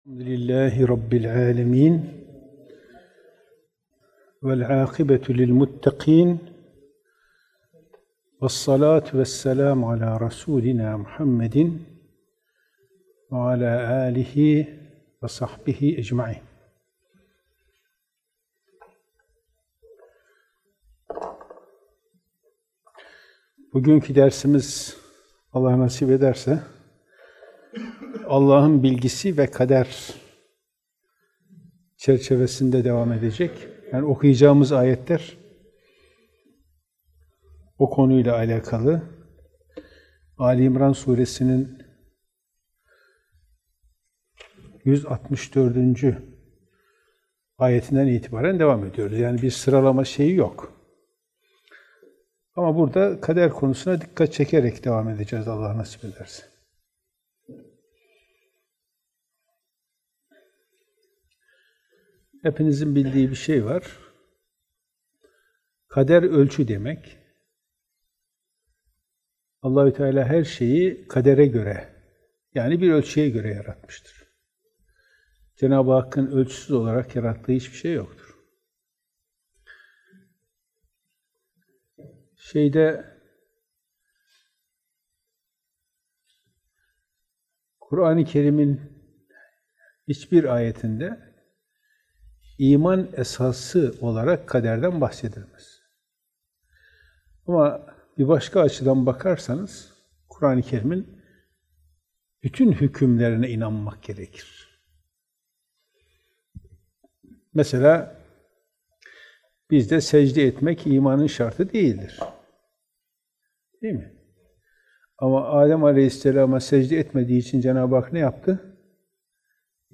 Kur'an Sohbetleri